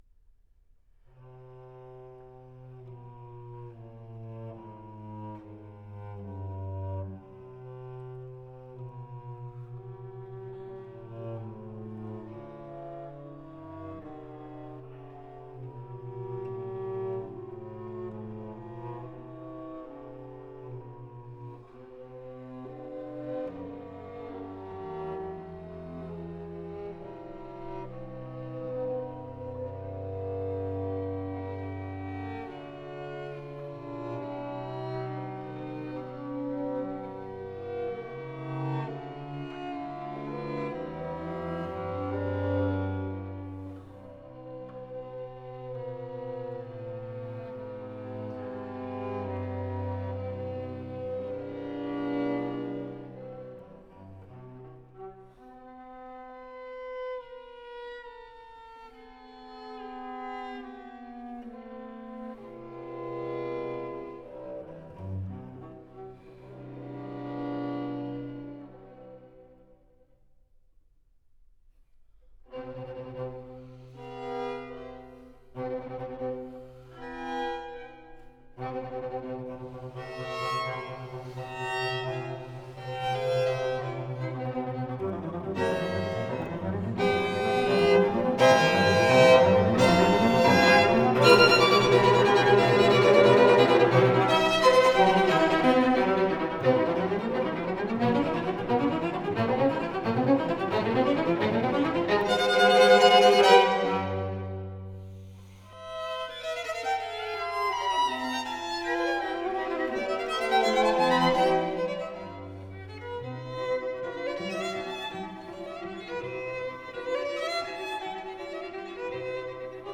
分类： 古典音乐、新世纪、纯音雅乐